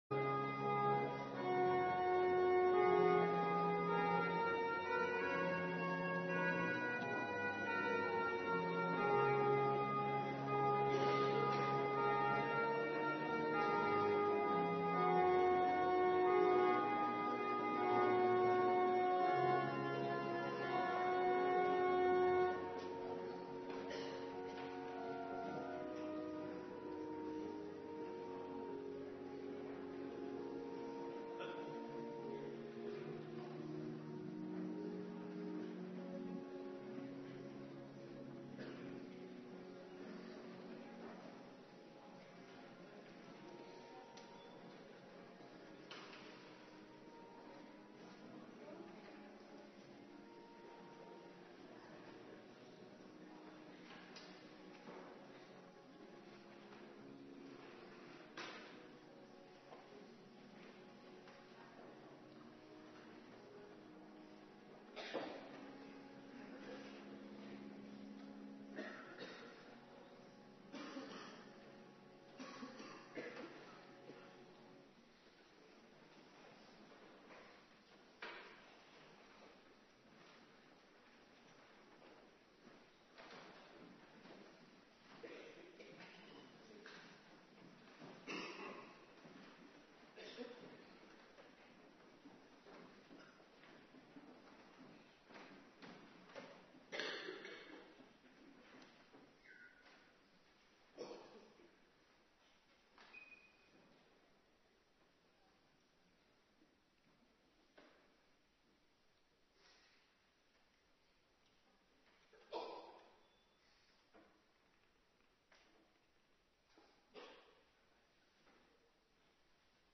Oudejaarsdienst
19:30 t/m 21:00 Locatie: Hervormde Gemeente Waarder Agenda: Kerkdiensten Extra info: Maleachi 3:13 t/m 4:3, met als tekst vers 18 Terugluisteren Oudjaarsavond Mal.3:13